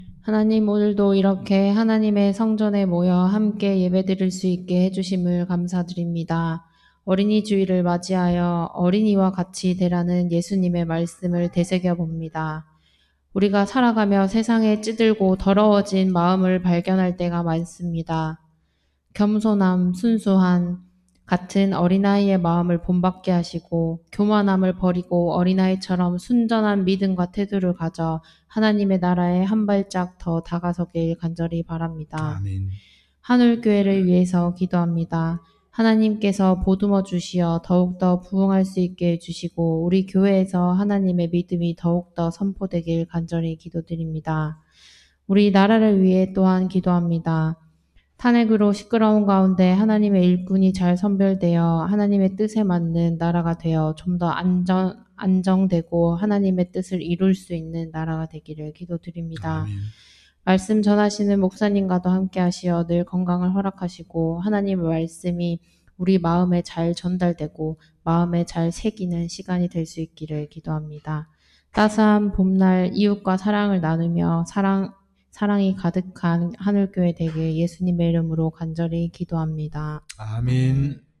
기도